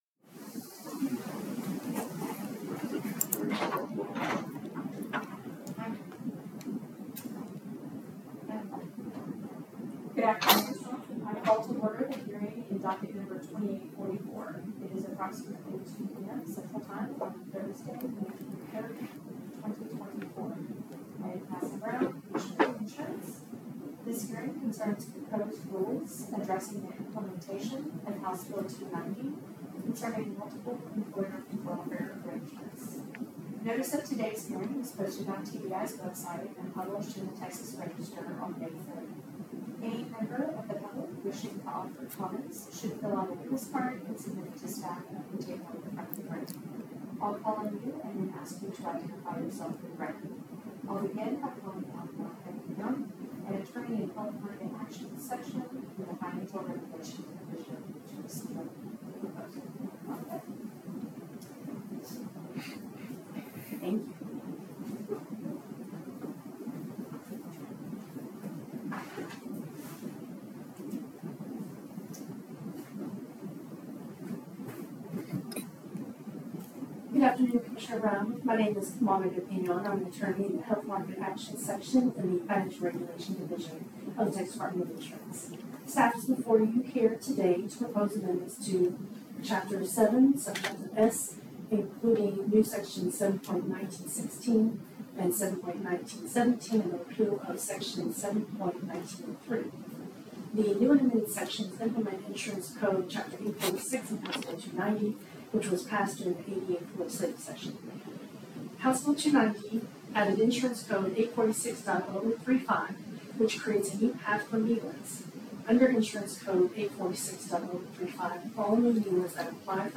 Hearing recording (.mp3, audio only)